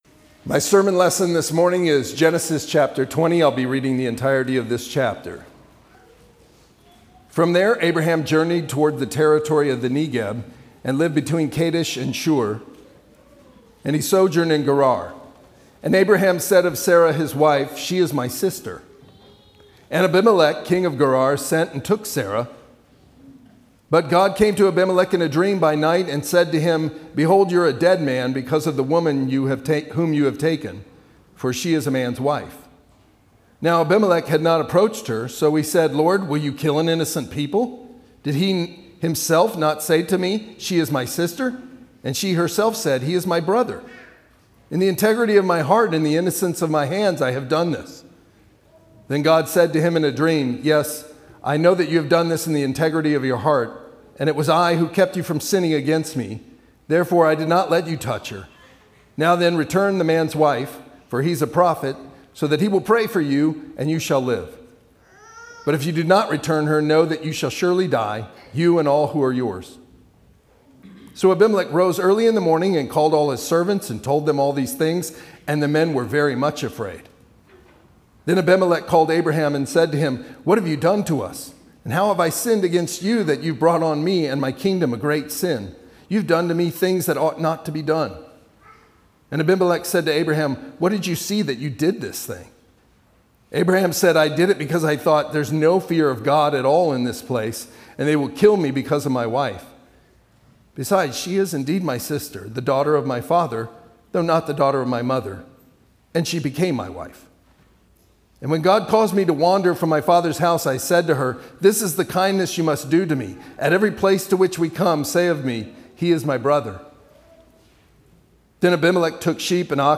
Sermons on Genesis Passage: Genesis 20 Service Type: Sunday worship Download Files Bulletin Topics